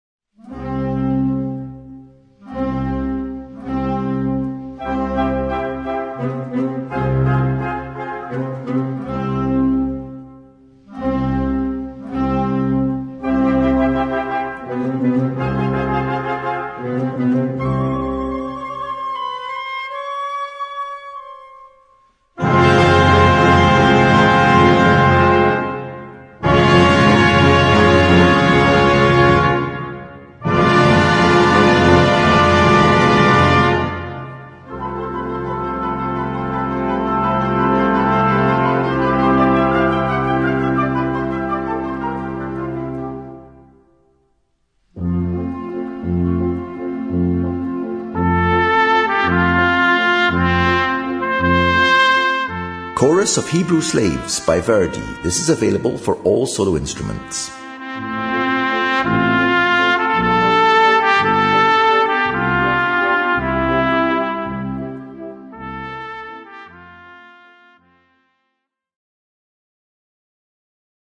Noten für Blasorchester, oder Brass Band.